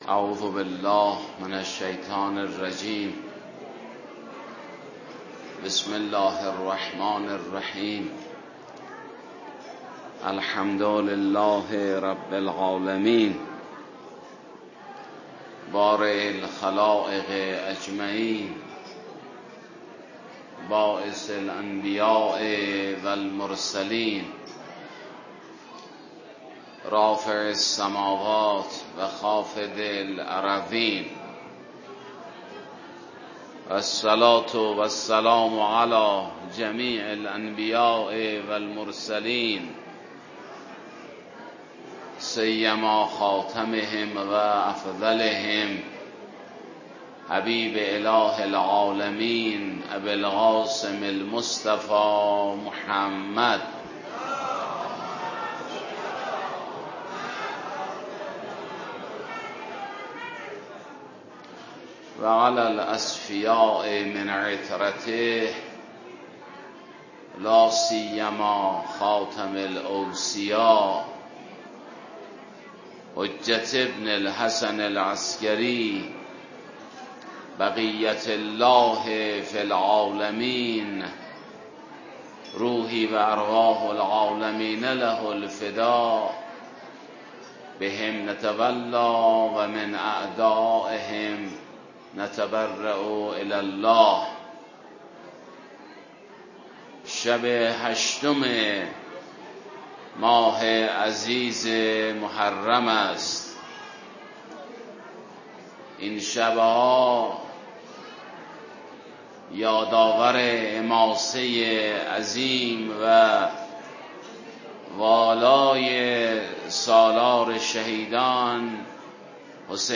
در شب هشتم محرم